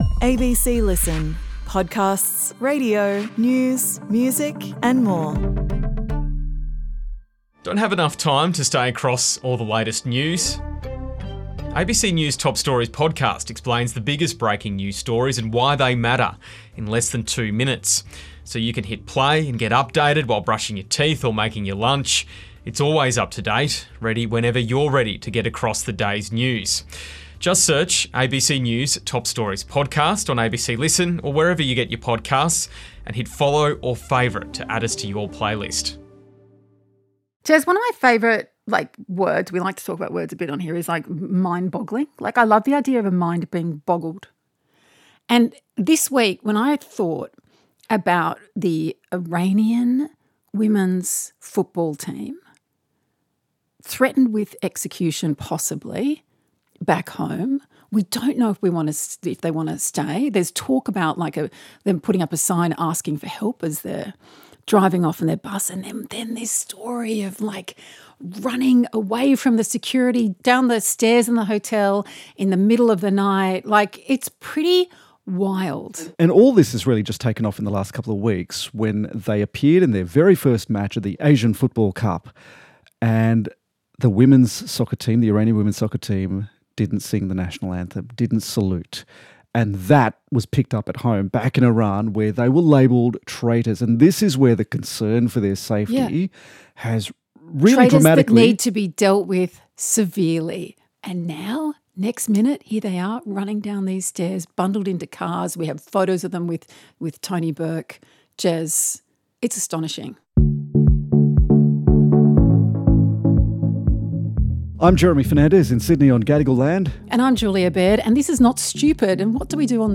Julia Baird and Jeremy Fernandez chat about the stories you're obsessed with, the stuff you've missed and the things that matter.